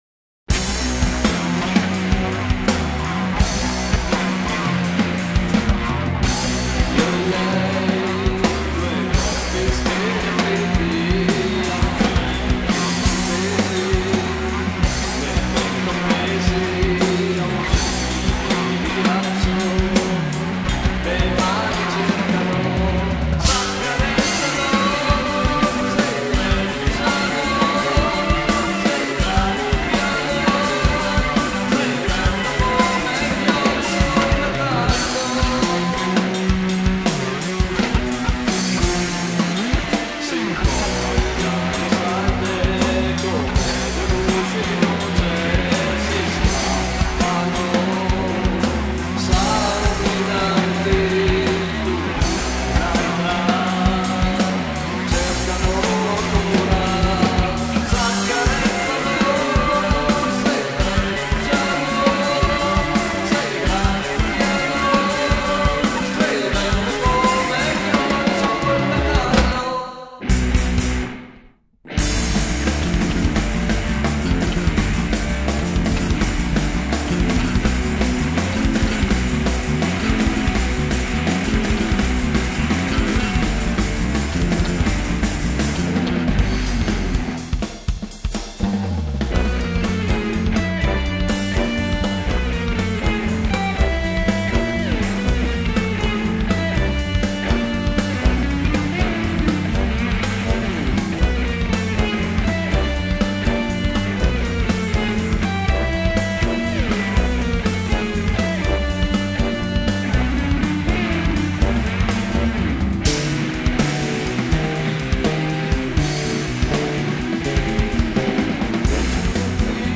Voice, keyboards and guitars
Drums
Bass